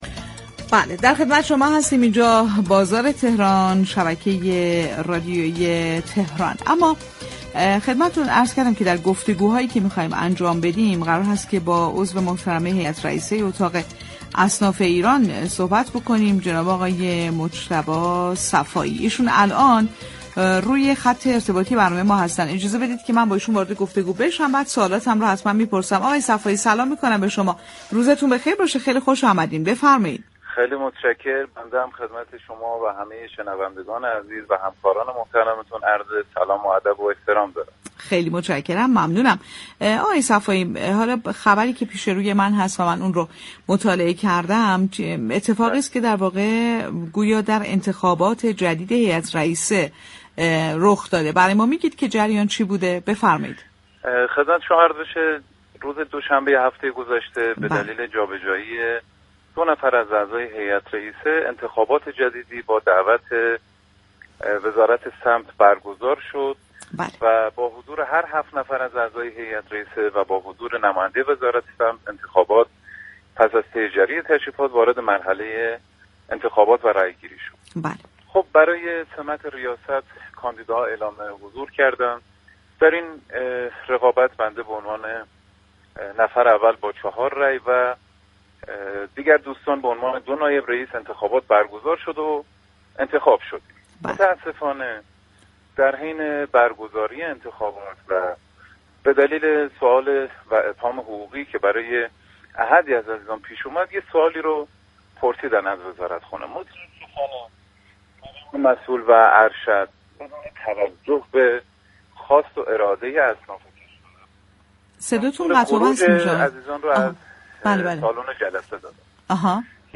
در گفتگو با برنامه «بازار تهران» رادیو تهران